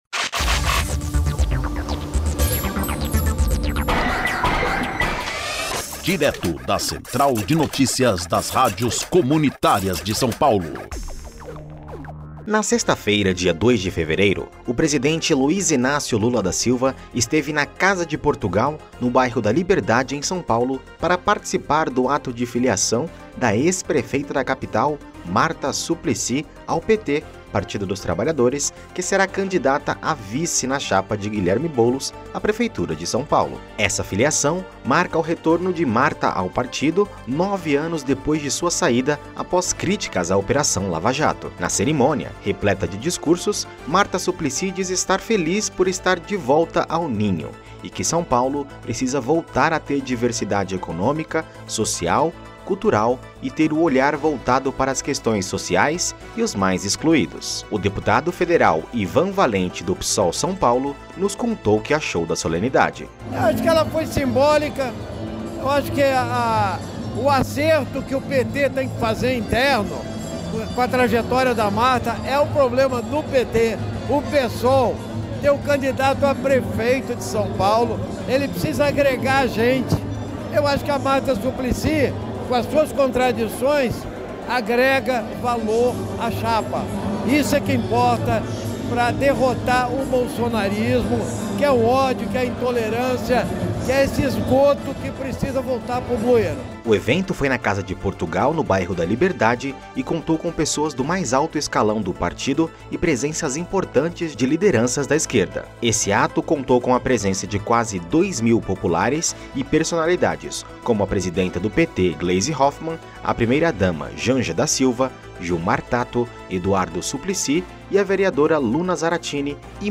Reportagem
O deputado federal Ivan Valente (PSOL-SP) nos contou o que achou da solenidade:
O evento foi na Casa de Portugal, no bairro da Liberdade e contou com pessoas do mais alto escalão do partido e presenças importantes de lideranças da esquerda.